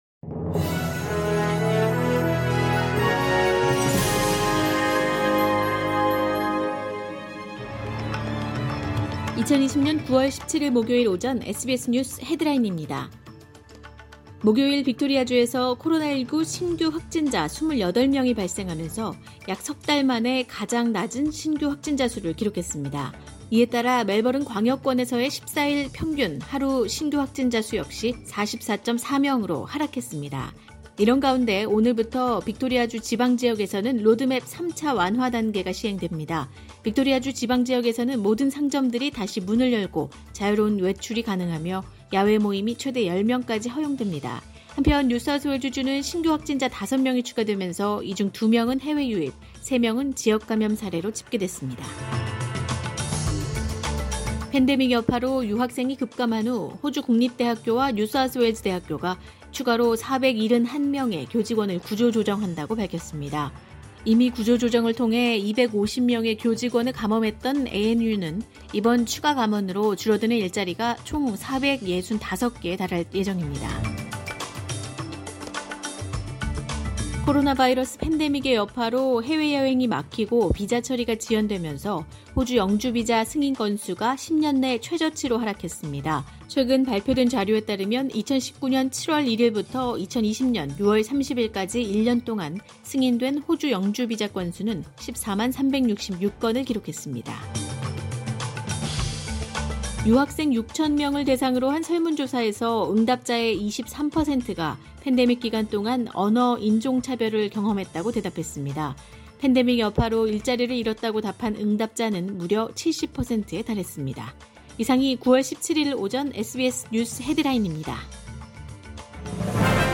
2020년 9월 17일 목요일 오전의 SBS 뉴스 헤드라인입니다.